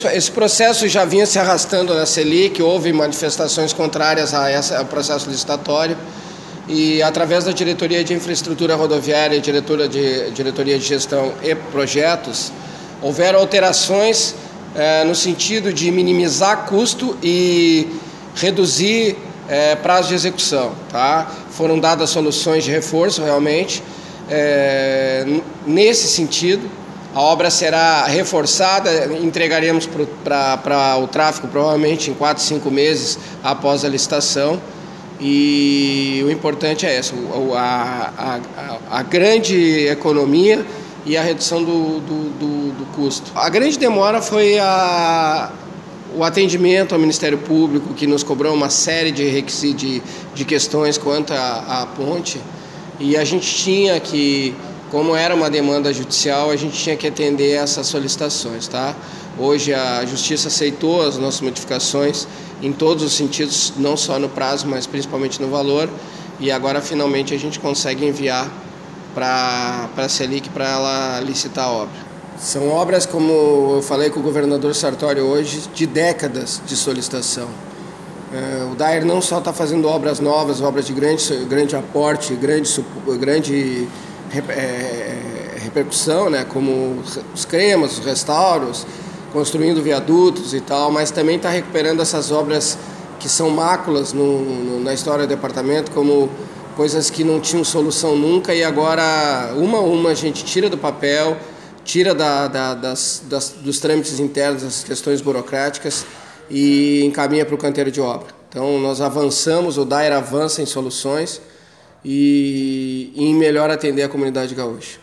Diretor-geral do Daer fala sobre licitação da obra na ponte da ERS-020